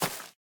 Minecraft Version Minecraft Version snapshot Latest Release | Latest Snapshot snapshot / assets / minecraft / sounds / block / vine / break4.ogg Compare With Compare With Latest Release | Latest Snapshot